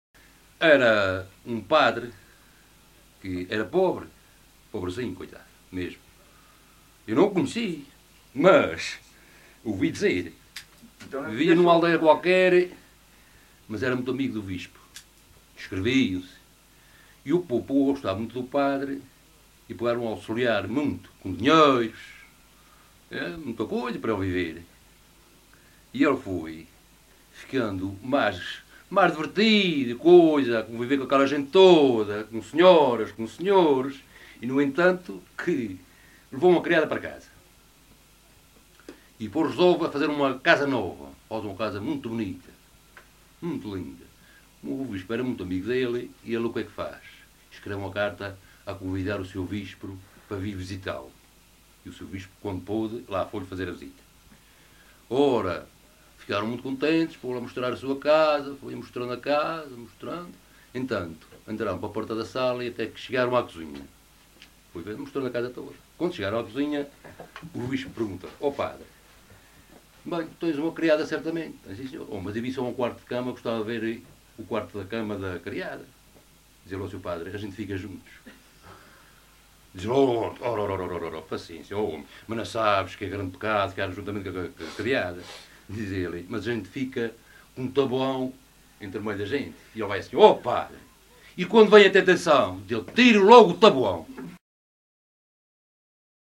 LocalidadeBandeiras (Madalena, Horta)